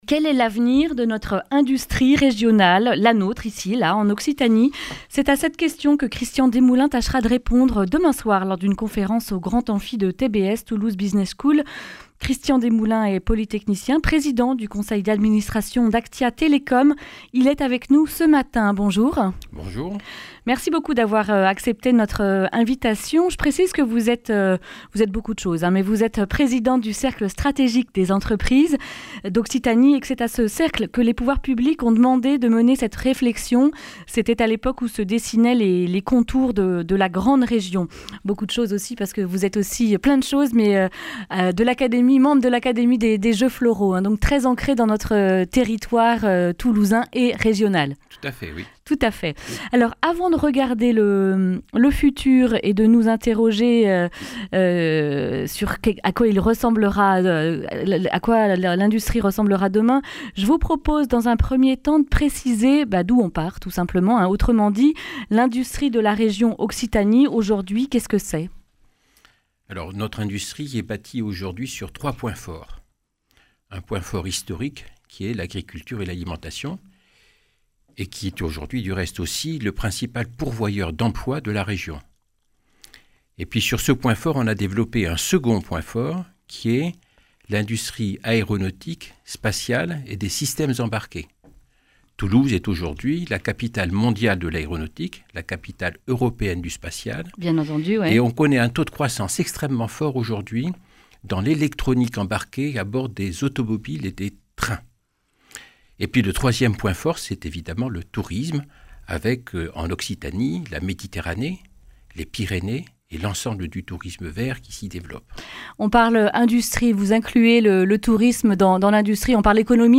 Accueil \ Emissions \ Information \ Régionale \ Le grand entretien \ A quoi pourrait ressembler notre industrie régionale 4.0 ?